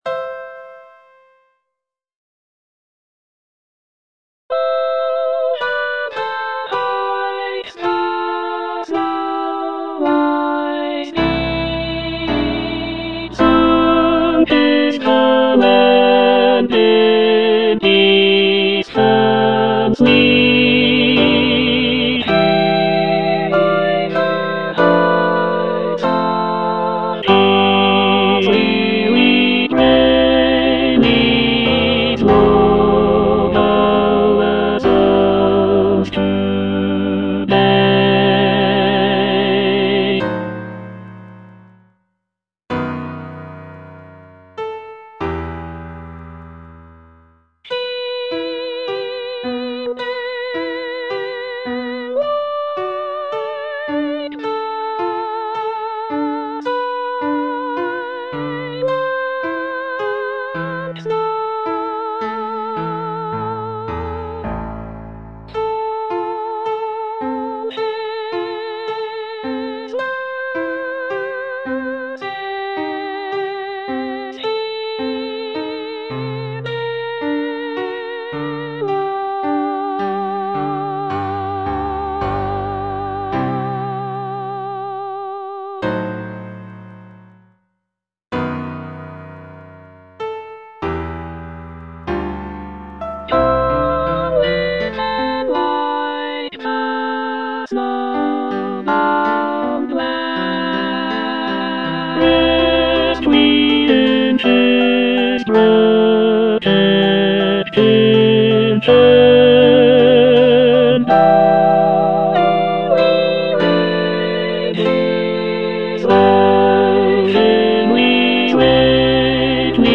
tenor II) (Emphasised voice and other voices) Ads stop